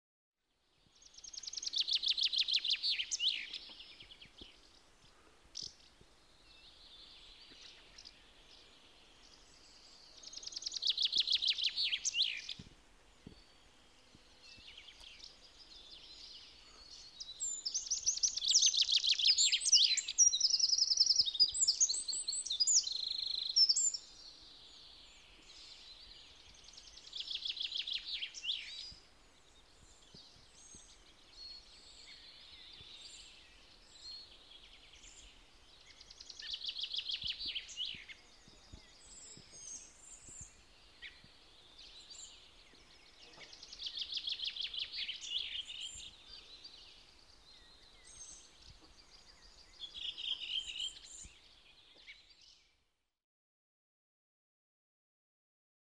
Peippokoiraan ponteva säe
Peippokoiraan ponteva säe on riemukkaimpia ylistyksiä keväälle! Sitä veisataan huhtikuun jälkipuolelta lähtien jokaisella metsähehtaarilla Suomenlahden saarilta tunturien tyville, sillä peippo on pajulinnun jälkeen Suomen runsaslukuisin lintulaji.
Taustalla kuuluu peukaloinen, räystäspääsky, keltasirkku ja punarastas.